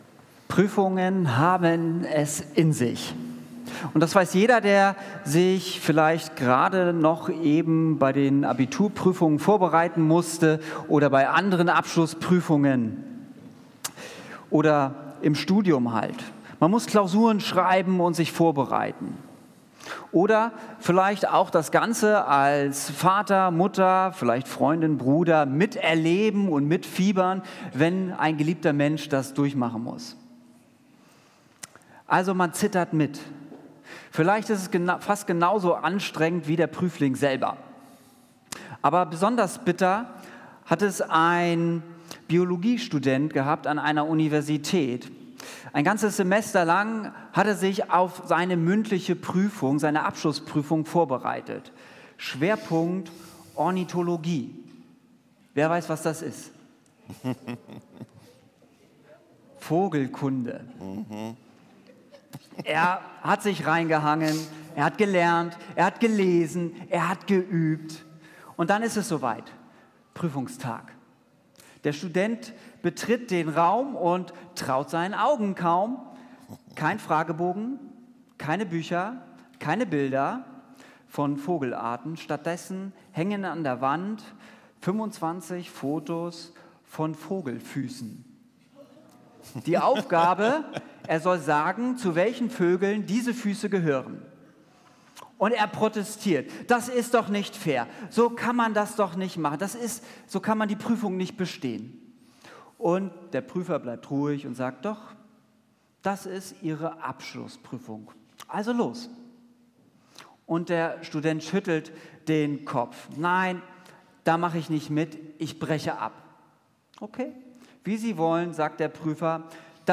Pfingstgottesdienst mit der IBC
Predigt